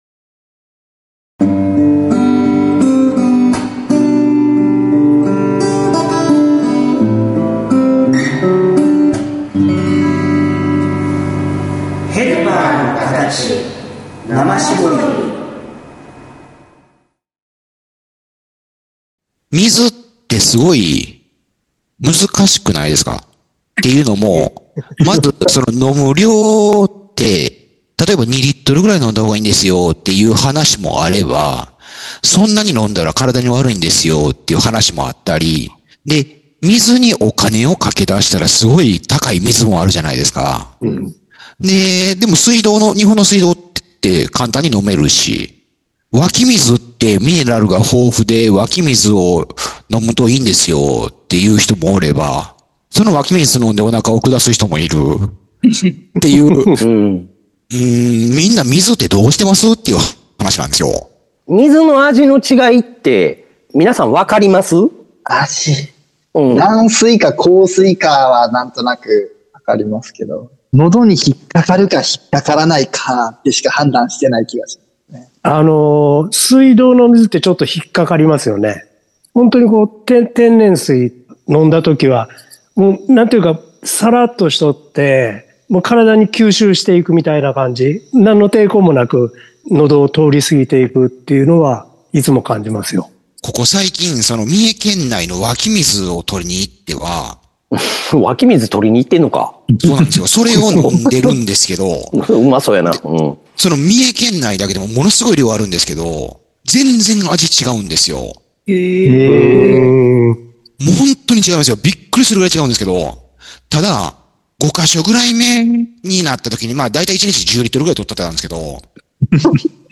＜今週のテーマ＞ 何気なく始まった「水」に ついてのトークを皮切りに 展開する〝秋口のグダグダ 井戸端会議〟の配信です。